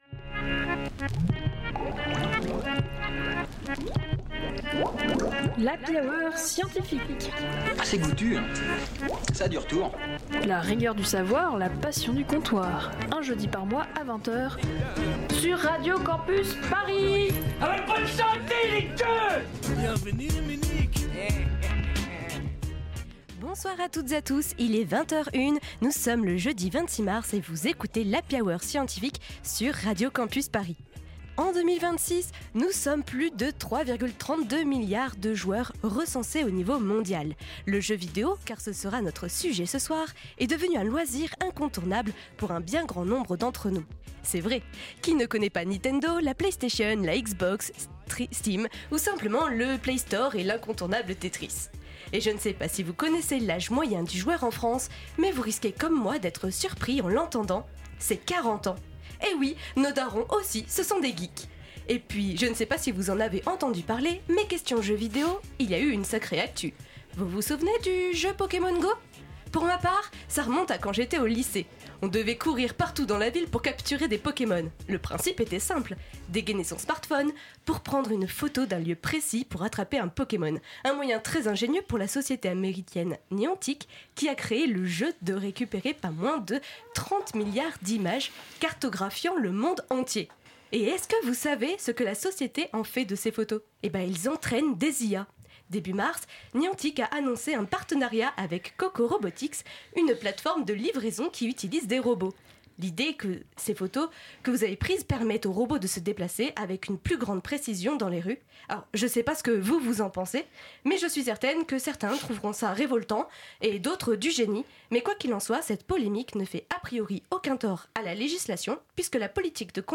Magazine Sciences